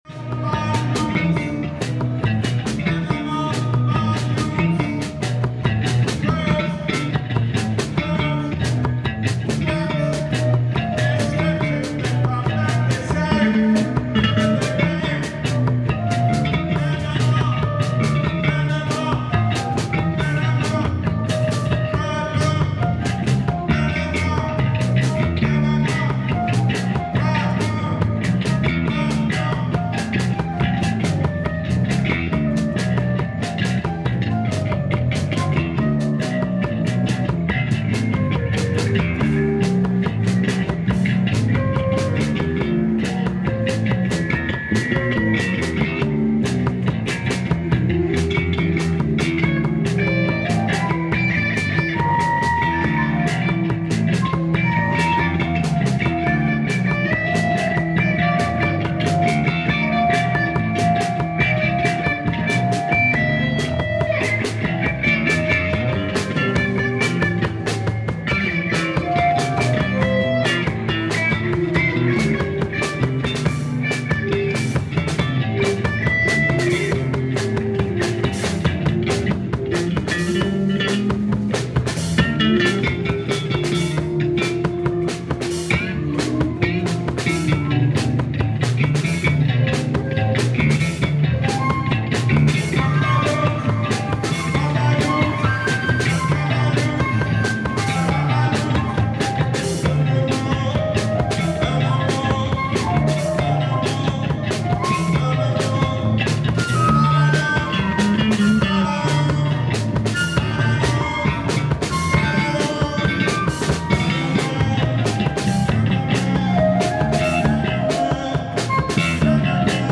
ALL MUSIC IS IMPROVISED ON SITE